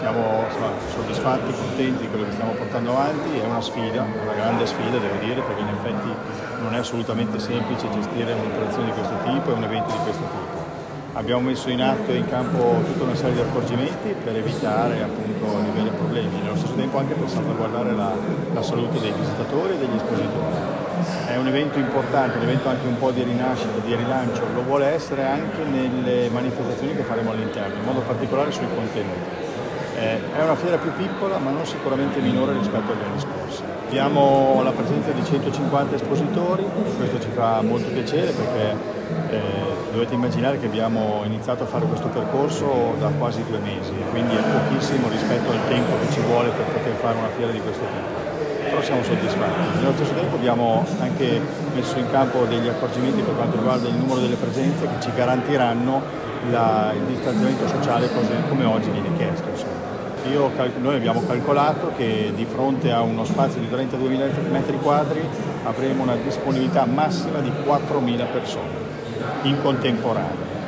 E’ stata ufficialmente inaugurata, nella serata di venerdì 4 settembre, la nuova edizione della Fiera Millenaria di Gonzaga, evento fieristico di punta della provincia di Mantova e non solo.